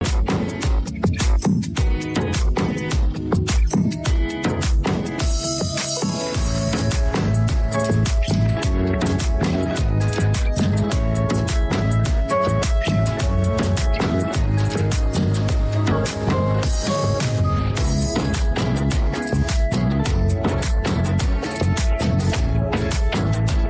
هوش مصنوعی پیشرفته حذف وکال با moises